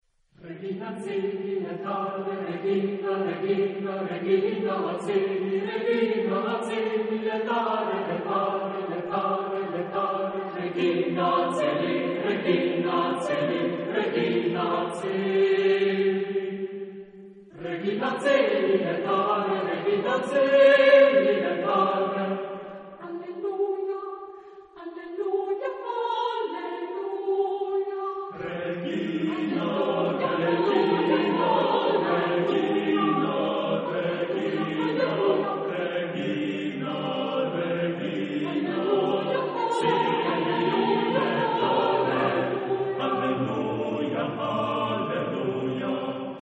Genre-Style-Forme : Sacré ; Hymne (sacré) ; Motet ; Antienne
Caractère de la pièce : vivant ; expressif
Type de choeur : SSAATTBB  (8 voix mixtes )
Tonalité : do majeur
Consultable sous : 20ème Sacré Acappella